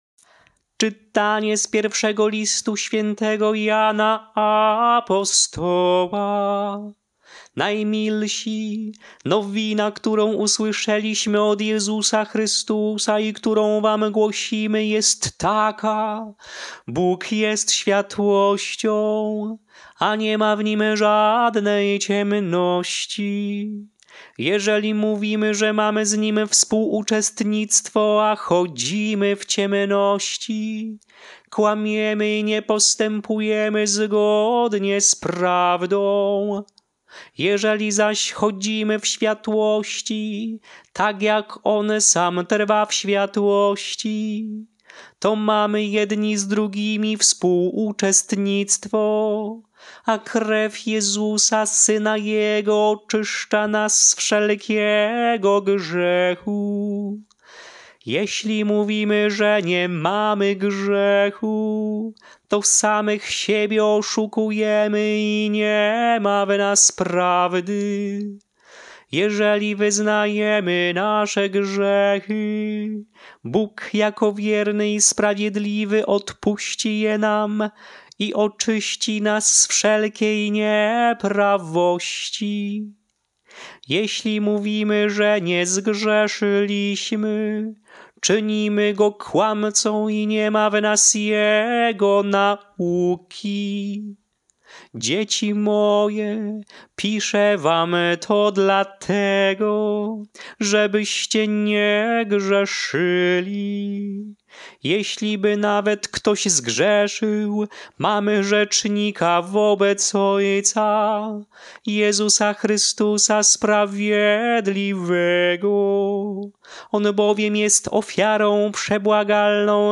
Śpiewane lekcje mszalne – Święto śś.
Melodie lekcji mszalnej przed Ewangelią na Święto śś. Młodzianków Męczenników: